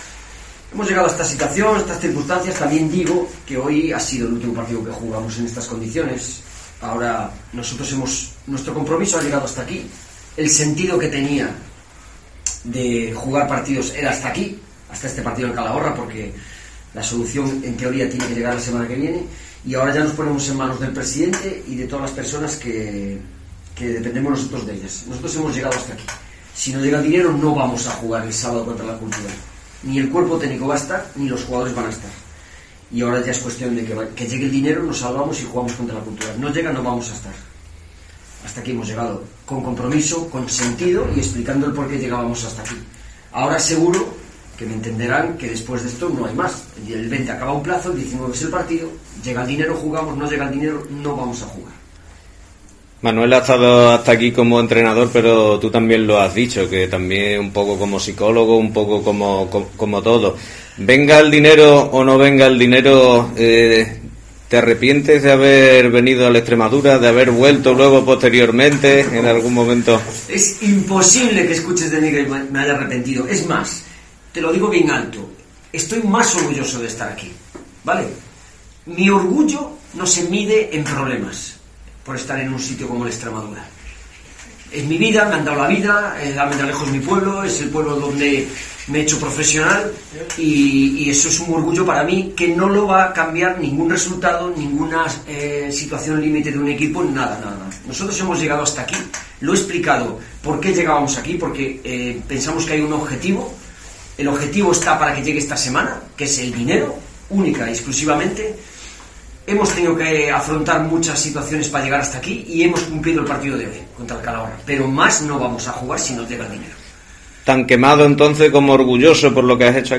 ha confirmado en sala de prensa que “este es el último partido que jugamos en estas condiciones.